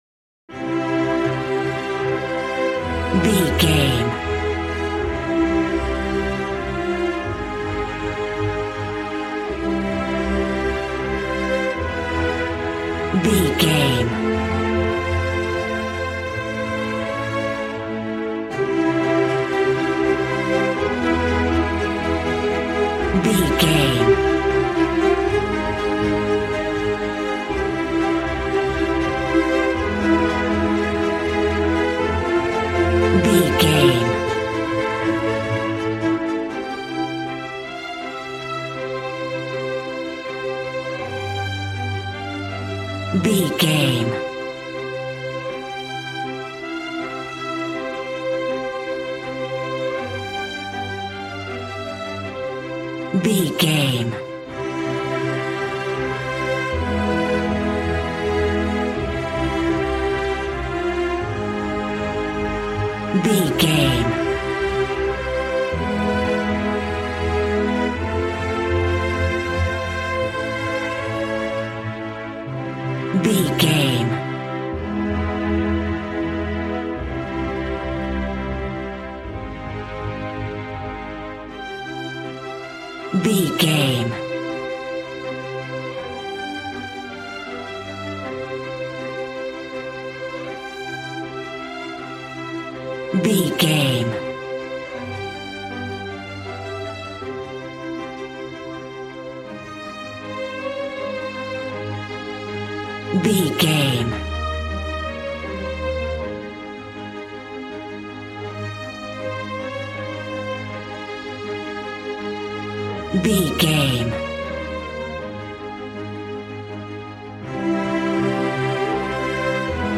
Regal and romantic, a classy piece of classical music.
Aeolian/Minor
regal
strings
brass